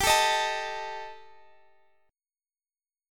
Gsus2#5 Chord (page 3)
Listen to Gsus2#5 strummed